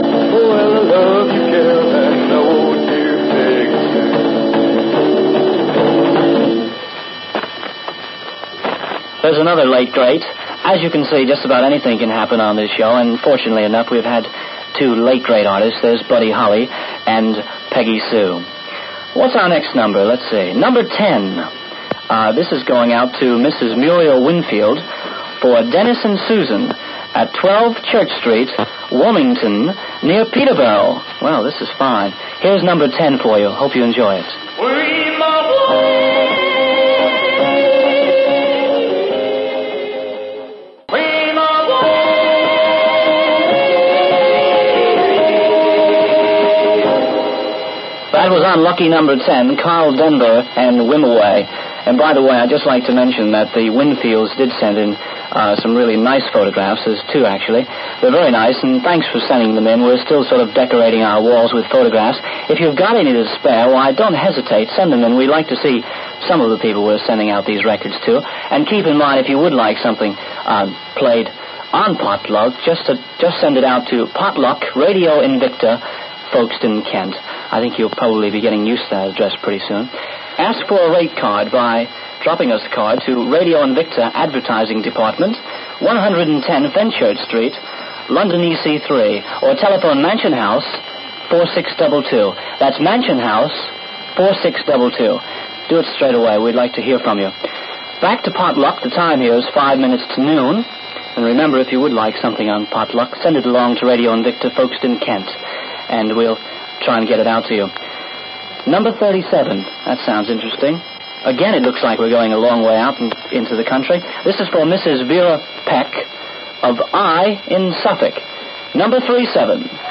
click to hear audio An unidentied DJ on Radio Invicta's Pot Luck programme getting confused by the address of a listener from Saffron Walden in Essex (duration 4 minutes 18 seconds)